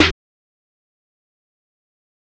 JEFFERDRIVE SNARE.wav